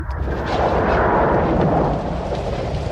WIND HOWLS Sound Effect.ogg
Original creative-commons licensed sounds for DJ's and music producers, recorded with high quality studio microphones.
[wind-howls-sound-effect]-2_jqf.mp3